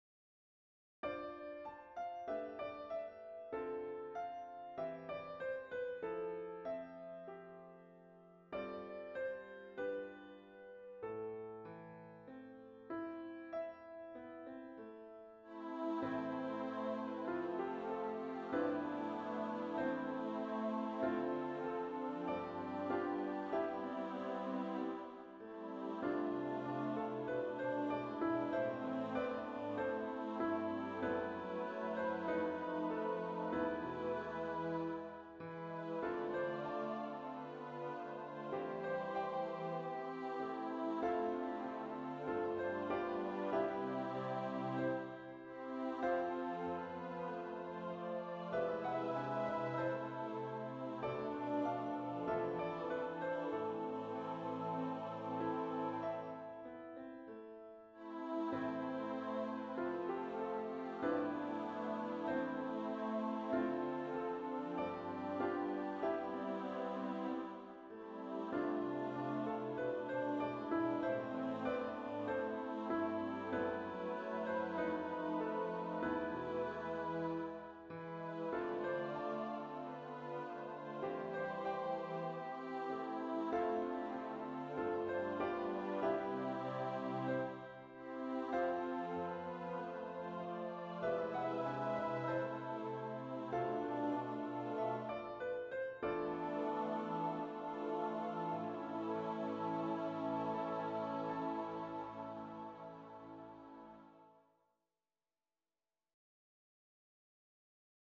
tonsatt för kör SATB med pianoackompanjemang
Lyssna (Finale Audio)